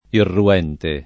vai all'elenco alfabetico delle voci ingrandisci il carattere 100% rimpicciolisci il carattere stampa invia tramite posta elettronica codividi su Facebook irruente [ irru- $ nte ] (meno bene irruento [ irru- $ nto ]) agg.